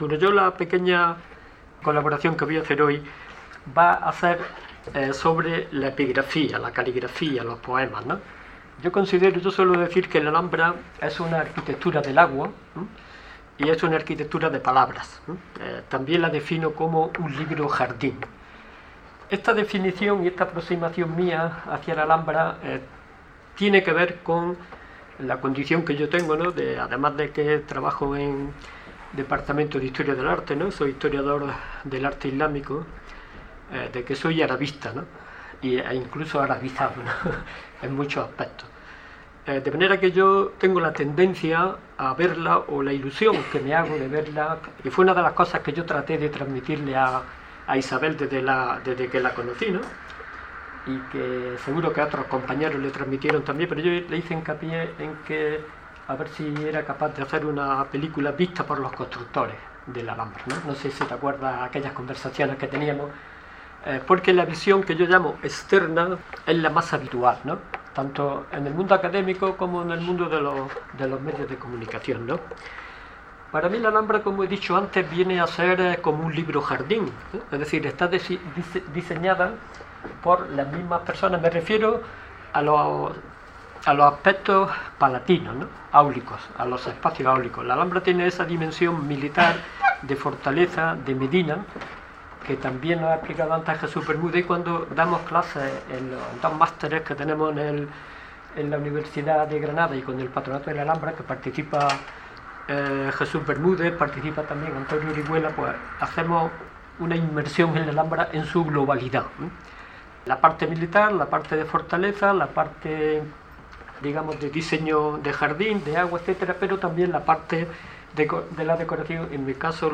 Podcast de una ponencia sobre la construcción de la Alhambra, su arquitectura y el aspecto simbólico de algunos aspectos de este extraordinario edificio.